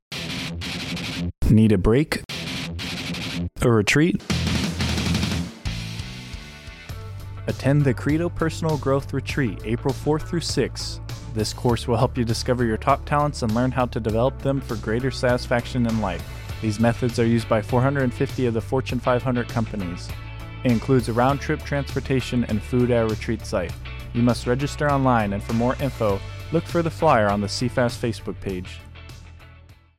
A radio quick kill spot on a personal growth retreat hosted by CREDO Japan.